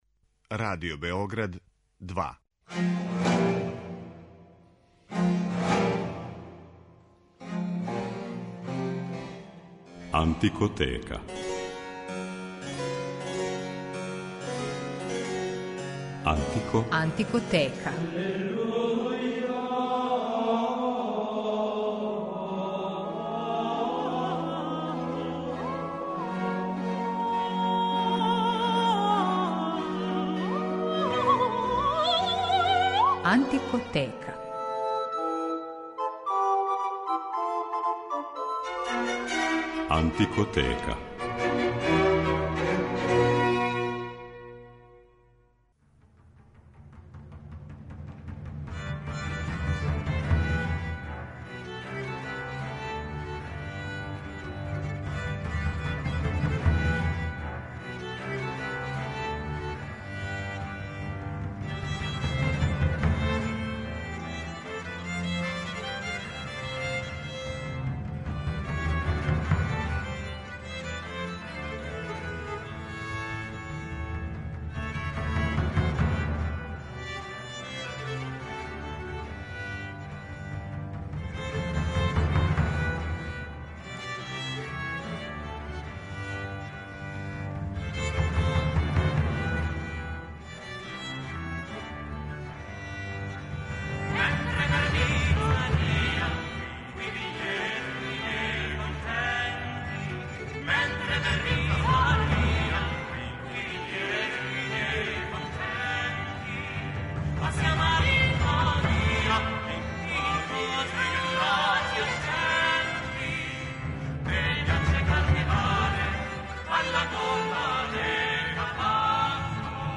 Емисија је посвећена карневалима и карневалској музици ренесансе и барока